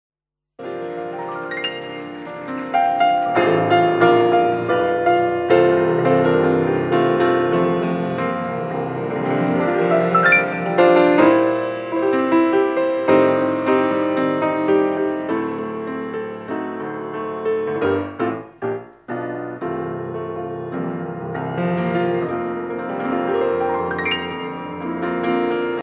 Edition discographique Live
Pièce musicale éditée